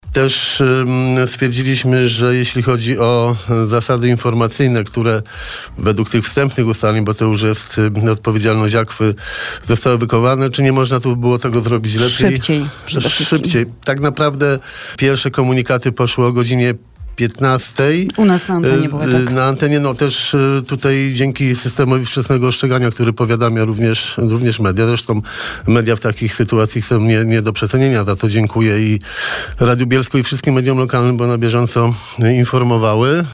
Jak referował na naszej antenie prezydent miasta, na spotkaniu stwierdzono, że wszystkie procedury zostały dochowane.
Trudno powiedzieć w takiej sytuacji, że na 100% wszystko poszło dobrze – dodał Jarosław Klimaszewski w trakcie porannej rozmowy.